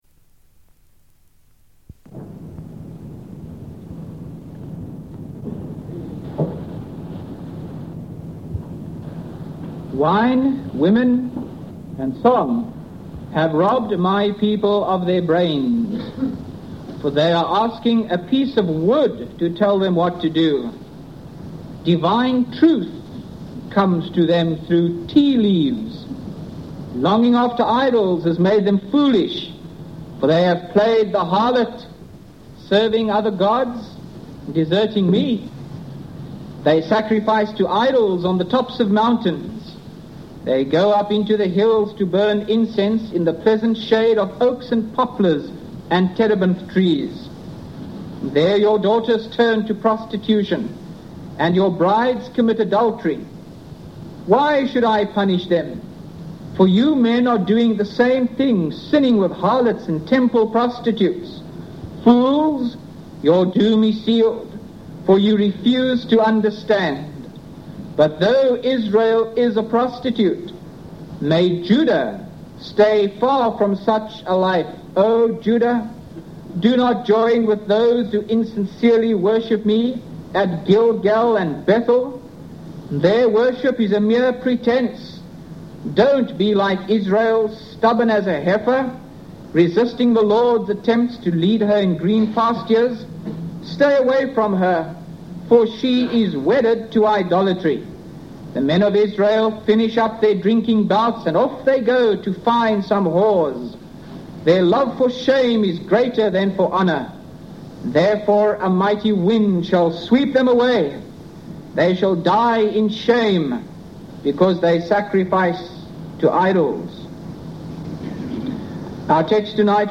by Frank Retief | Feb 3, 2025 | Frank's Sermons (St James) | 0 comments